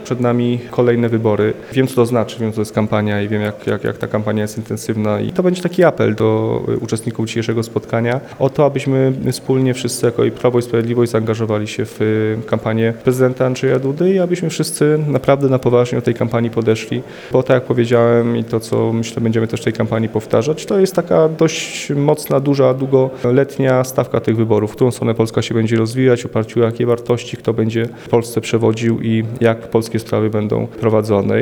Mówi europoseł Tomasz Poręba, obecny na spotkaniu opłatkowo-noworocznym w Mielcu.